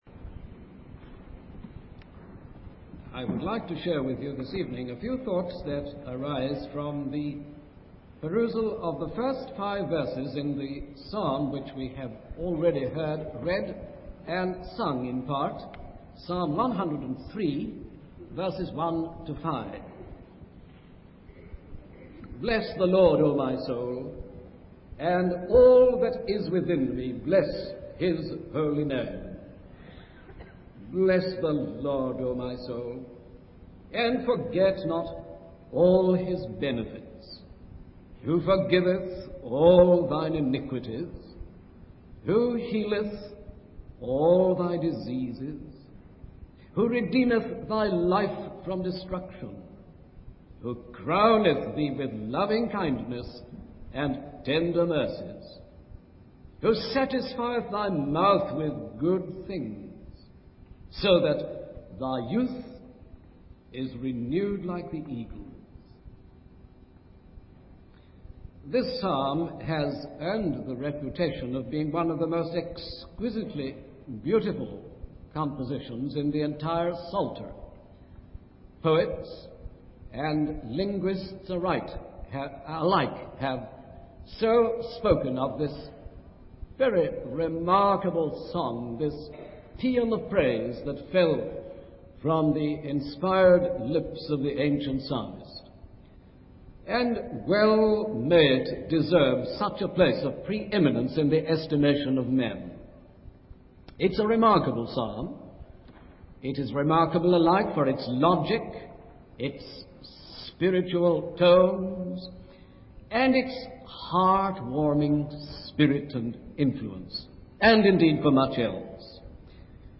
In this sermon, the speaker reflects on the joy and fire that comes from experiencing the word of God.